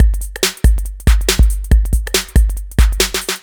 140 Ass Track Fill.wav